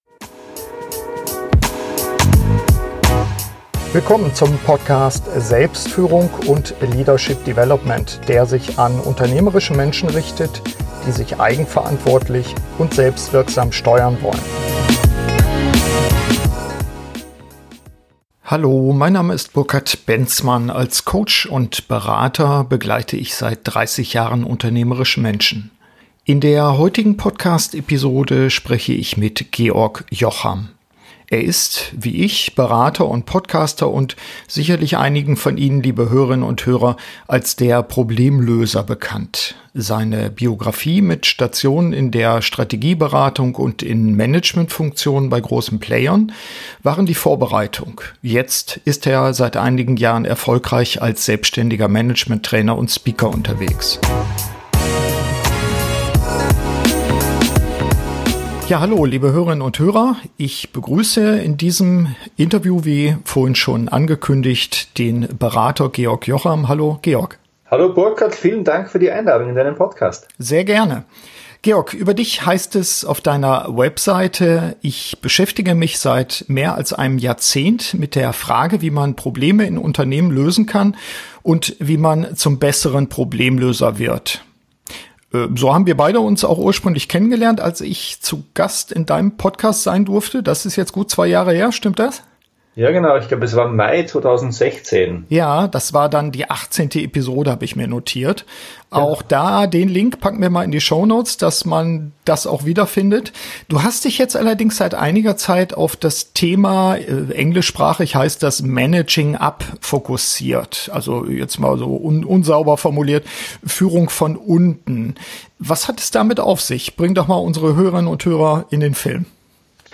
SF80 Managing Up: Führung von unten - Interview